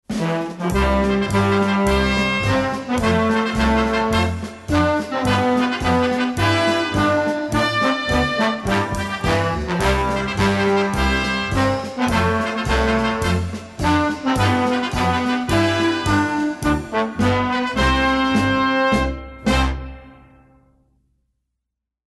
Музыка для торжественного поднятия флага